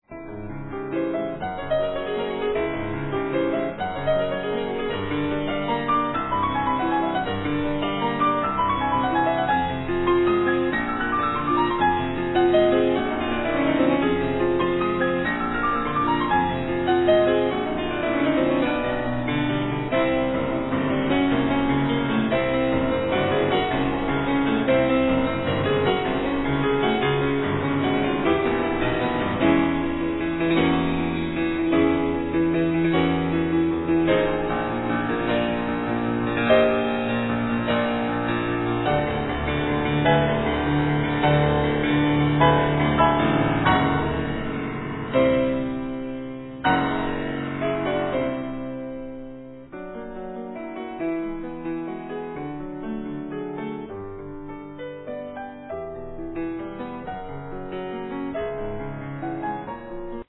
Piano, Vocal